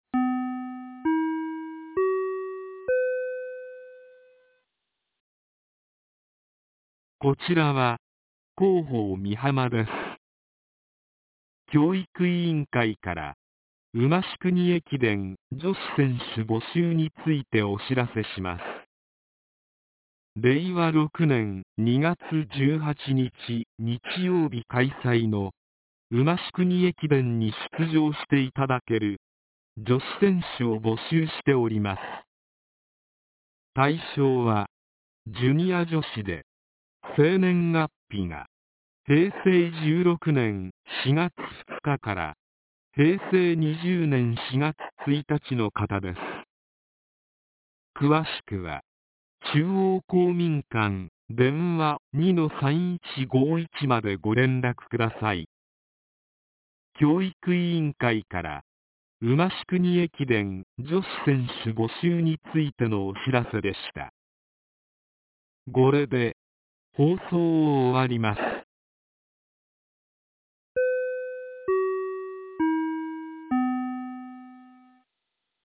■防災行政無線情報■
放送内容は下記の通りです。